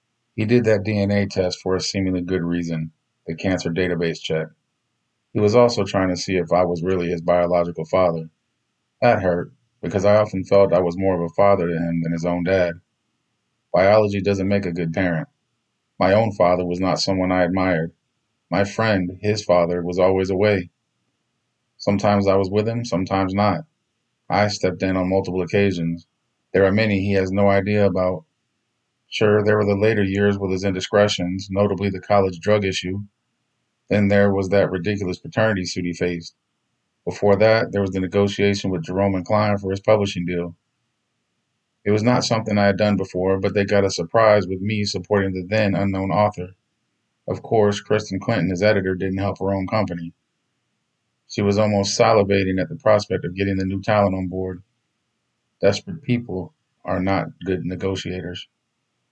Its also for sale as an audio book on Audible UK, Audible US, other Audible countries, and Apple platforms.